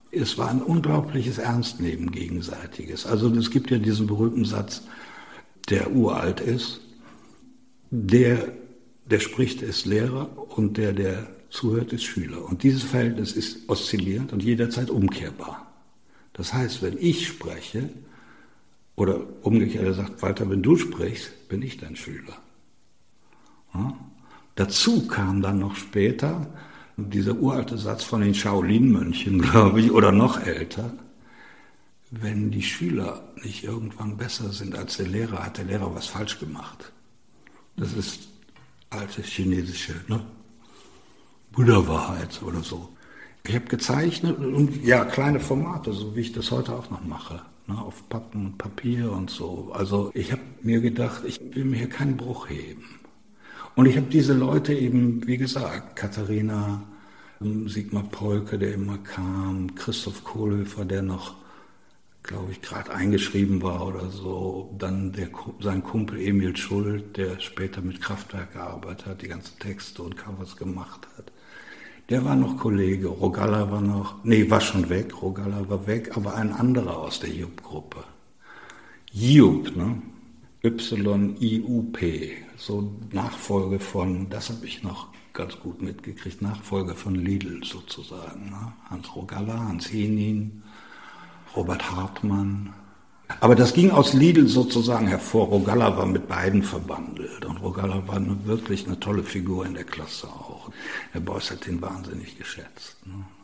Interview Audioarchiv Kunst: Walter Dahn über das Lehren von Kunst
Walter Dahn spricht im Interview über das Verhältnis zwischen Lehrenden und Lernenden.